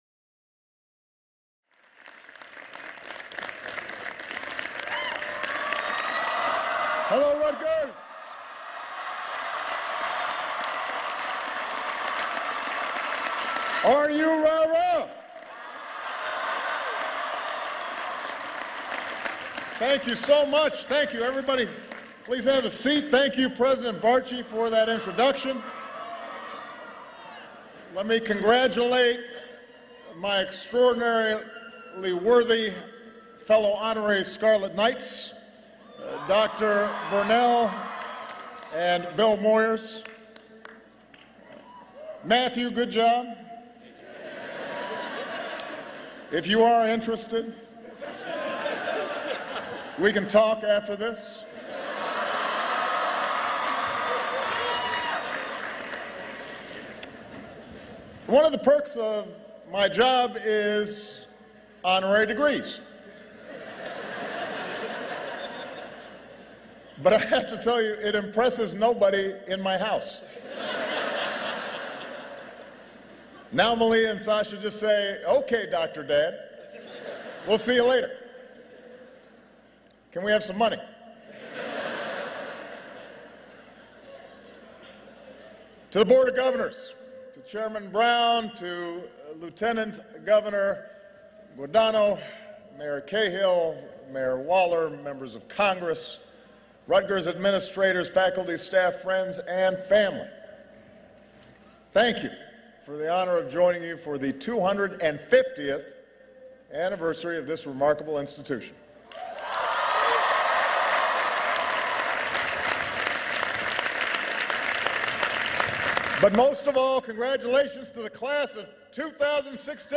U.S. President Barack Obama speaks at the 2016 Rutgers University-New Brunswick and Rutgers Biomedical and Health Sciences commencement convocation